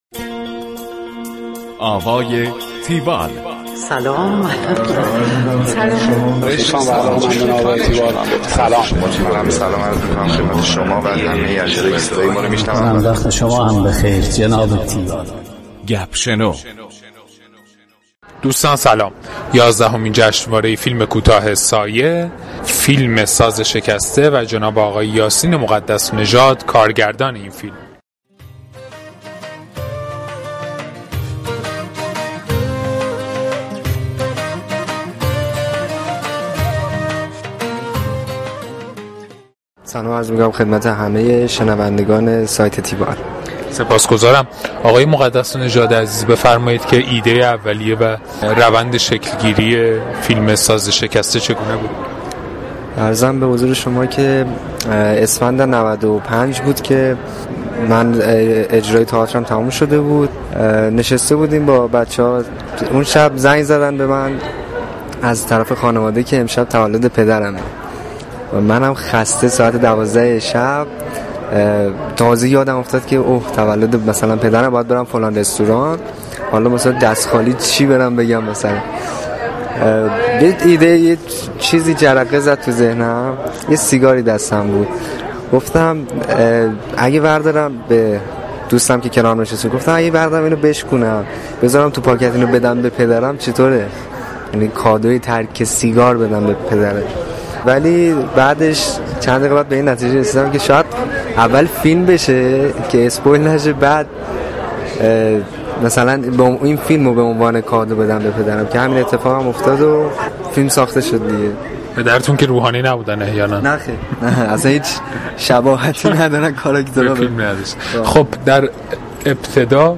گفتگو کننده: